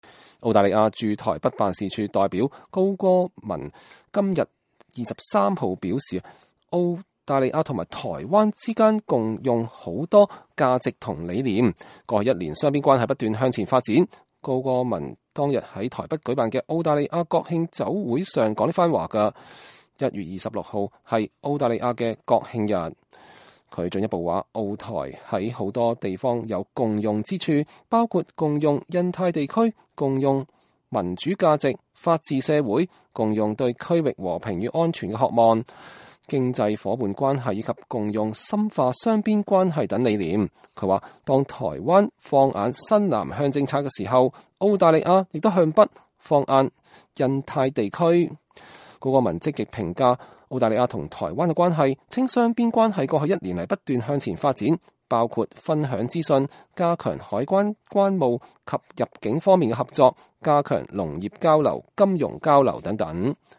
澳大利亞駐台北辦事處代表高戈銳（Gary Cowan）今天（1月23日）表示，澳台之間共享許多價值與理念，過去一年雙邊關係不斷向前發展。高戈銳當天在台北舉辦的澳大利亞國慶酒會上說的這番話。